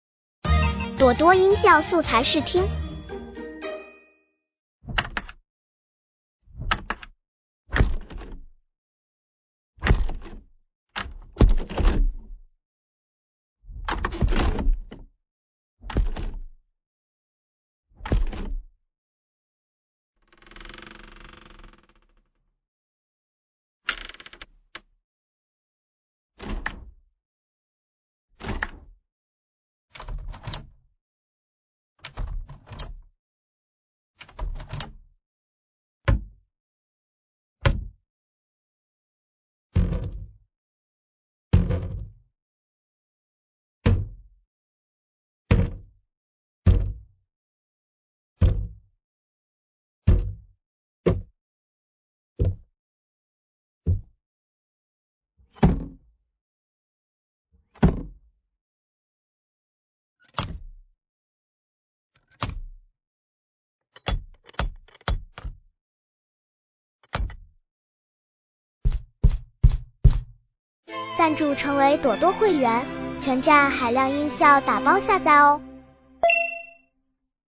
现代开门关门声音效素材合集包打开房门卧室门防盗门开关音效-朵多网
类    型：现代开门关门声音效素材合集包
从吱呀作响的百年古宅木门，到未来感十足的电子感应舱门
从急促慌张的夺门而出，到犹疑试探的缓慢推门
✅ 影视级采样品质｜48kHz/24bit无损录制，细微摩擦声真实可辨